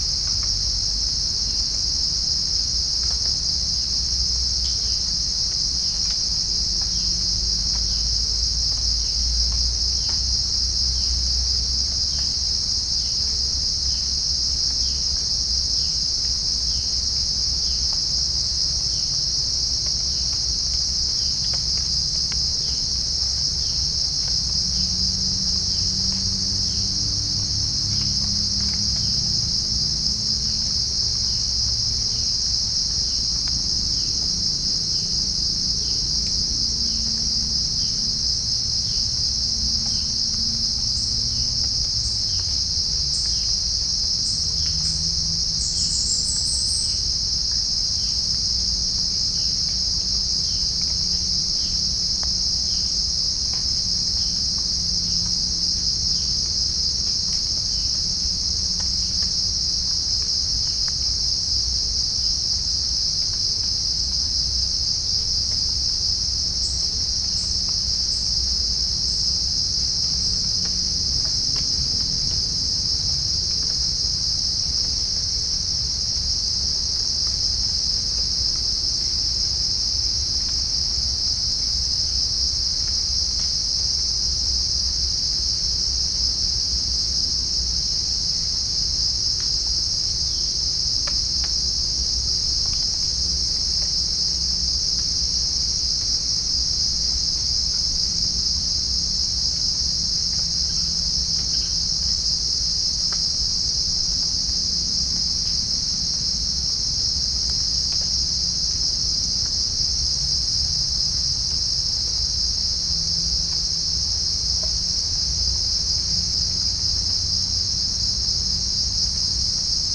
Chalcophaps indica
Pycnonotus goiavier
Pycnonotus aurigaster
Halcyon smyrnensis
Orthotomus ruficeps
Dicaeum trigonostigma